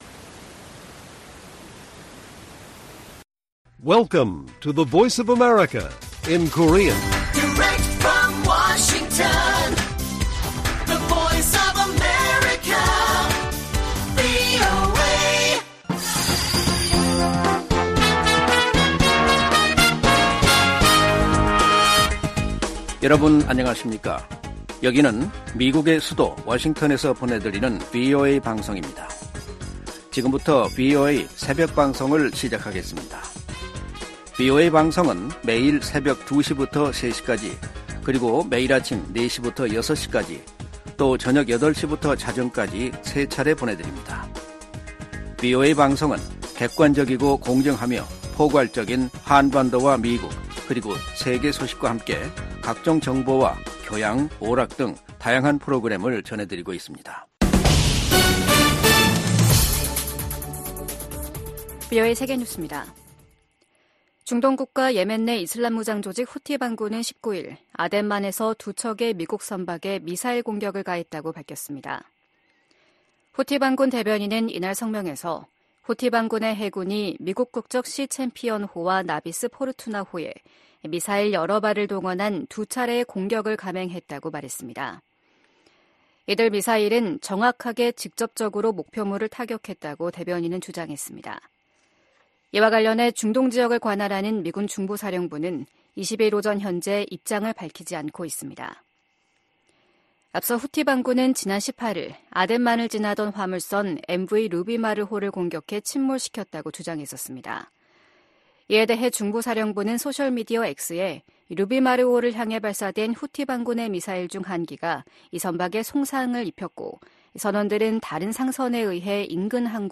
VOA 한국어 '출발 뉴스 쇼', 2024년 2월 21일 방송입니다. 블라디미르 푸틴 러시아 대통령이 김정은 북한 국무위원장에게 러시아산 승용차를 선물했습니다. 미 국무부가 역내 긴장 고조는 미국 탓이라는 북한의 주장을 일축하고, 미국과 동맹의 연합훈련은 합법적이이라고 강조했습니다. 미 국무부는 유엔 북한인권조사위원회(COI) 최종 보고서 발표 10주년을 맞아 북한 정권에 인권 문제 해결을 촉구했습니다.